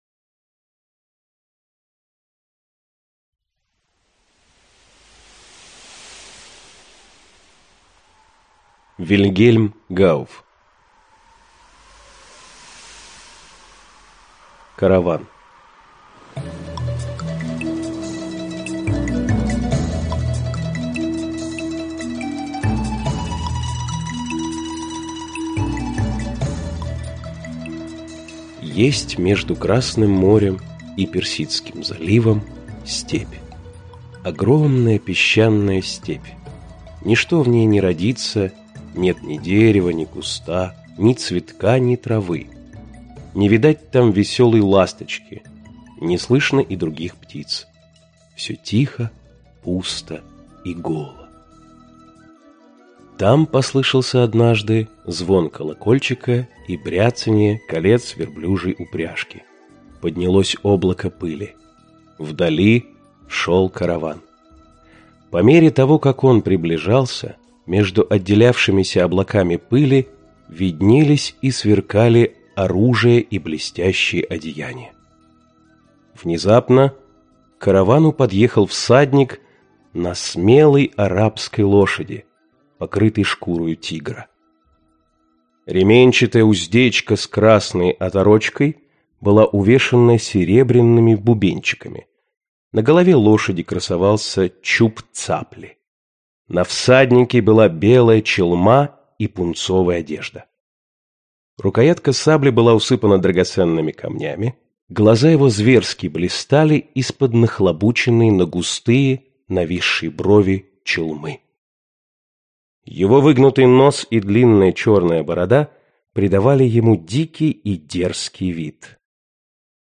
Аудиокнига Сказка о Калифе-аисте | Библиотека аудиокниг